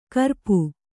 ♪ karpu